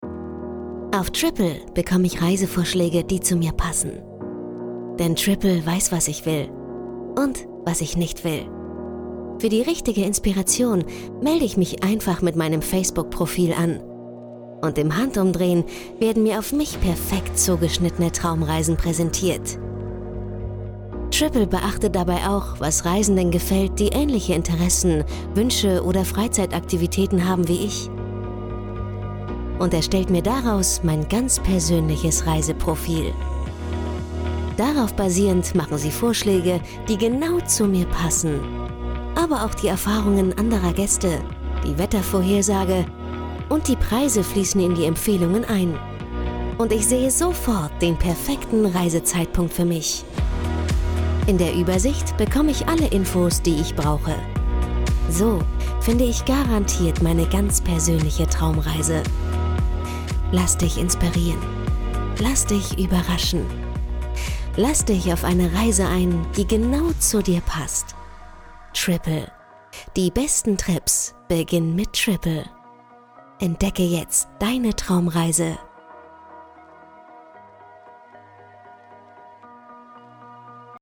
hell, fein, zart, markant, sehr variabel
Werbung Internet
Commercial (Werbung)